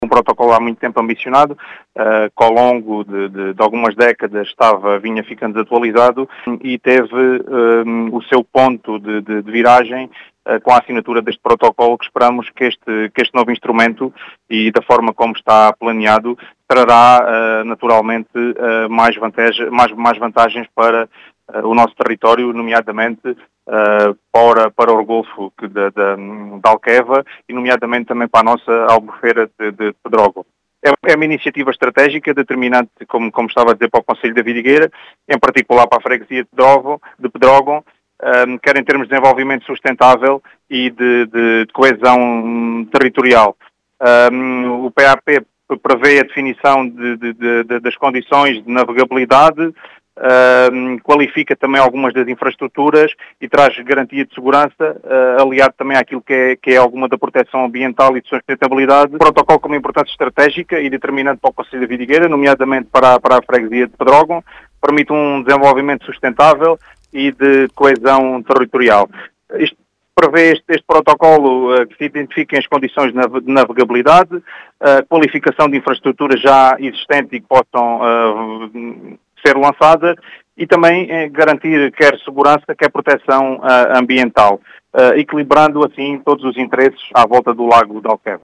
Em declarações à Rádio Vidigueira, Ricardo Bonito, presidente da Câmara Municipal de Vidigueira, município integrante da Associação Transfronteiriça Lago Alqueva, realçou o carácter “estratégico” deste plano para Alqueva e Pedrógão.